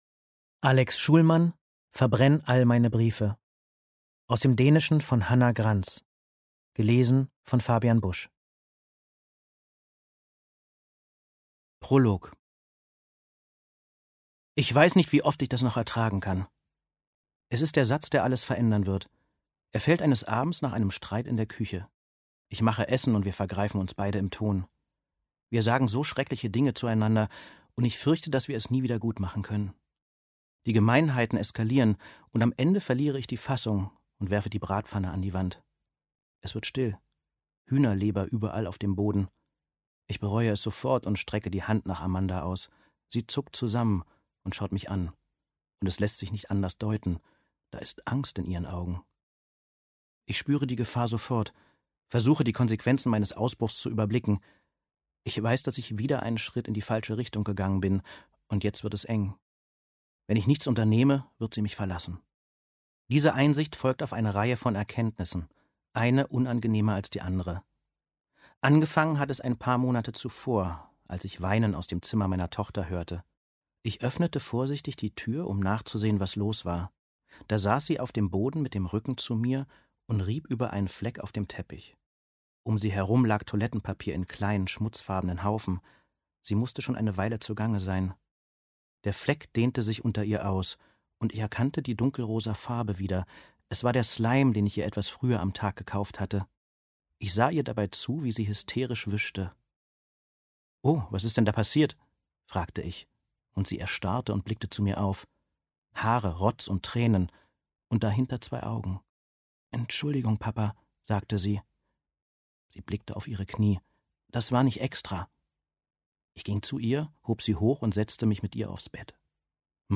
Ungekürzte Lesung mit Fabian Busch